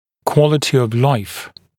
[‘kwɔlətɪ əv laɪf][‘куолэти ов лайф]качество жизни